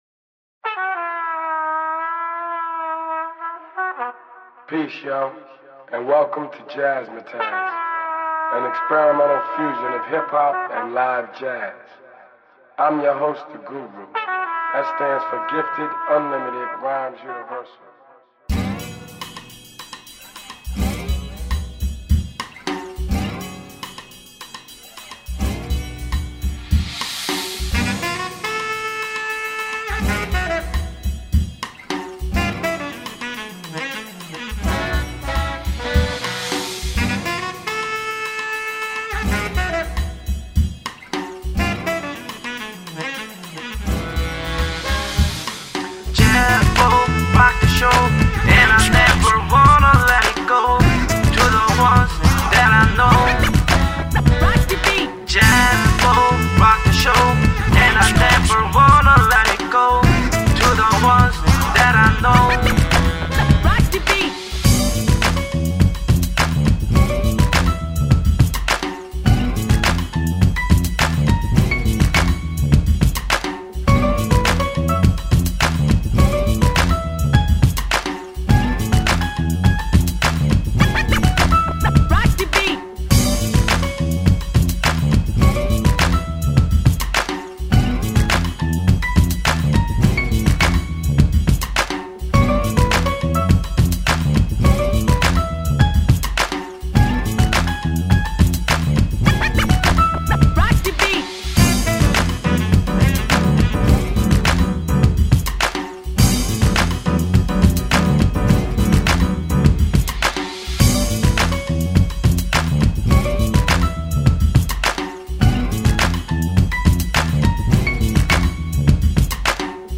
* 이 곡은 그의 랩이 들어갈 자리를 비워 둡니다.